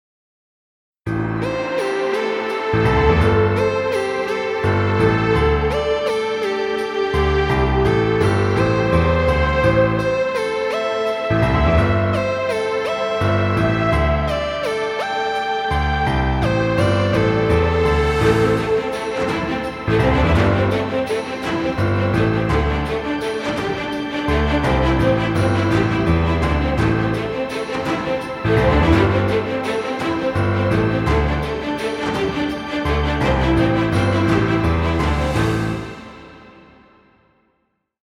Epic music, exciting intro, or battle scenes.